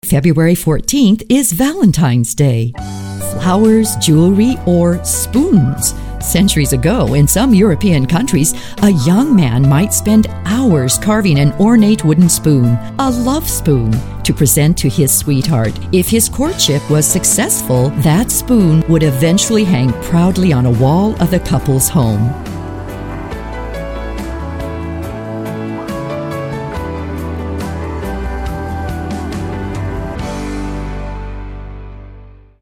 This series of :30-second vignettes on Valentine's Day history and traditions will provide a distinctive introduction to your sponsors' ads . . . and help you make more sales!